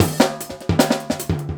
LOOP39--02-L.wav